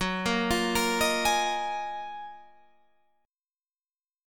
GbM13 Chord